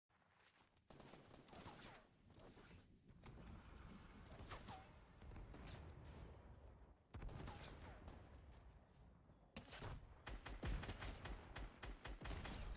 OG Fortnite - Throwing All The Explosives, For No Reason